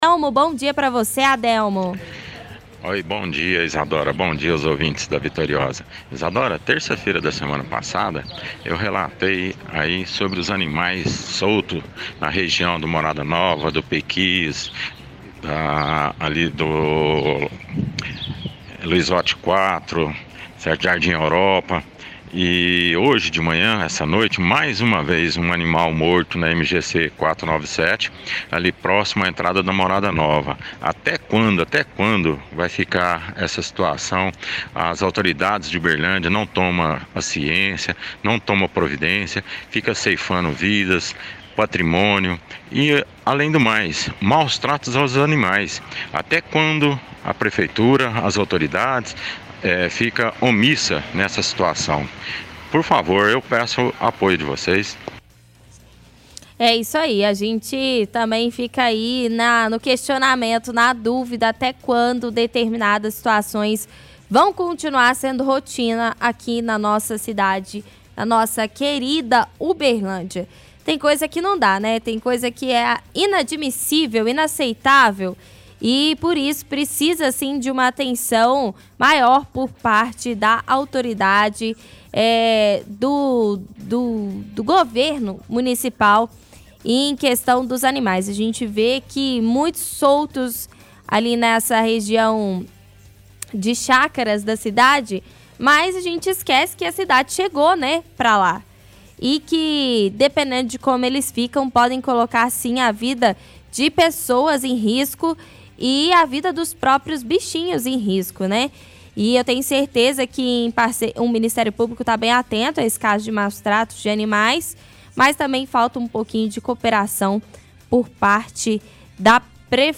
– Ouvinte fala que relatou na semana passada problemas envolvendo animais soltos nas vias da cidade em bairros como o Morada Nova e Pequis.